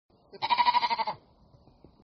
Download Sheep sound effect for free.
Sheep